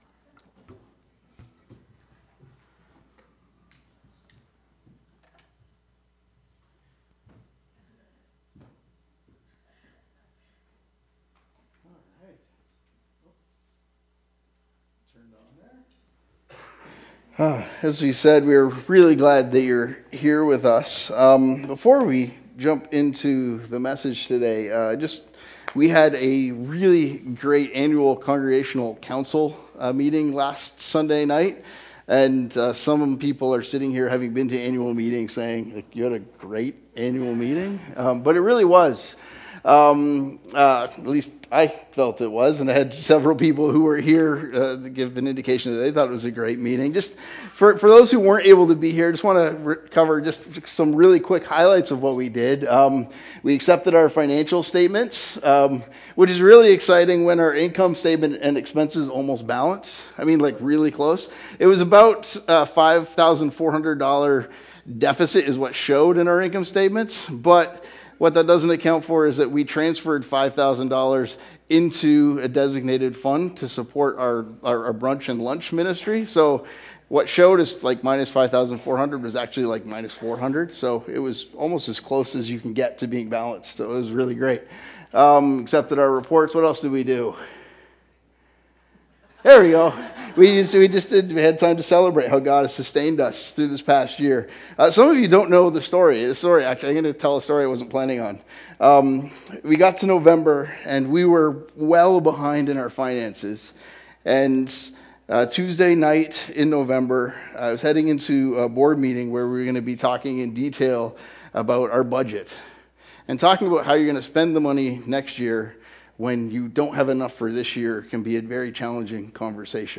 Sermons | Covenant Christian Community Church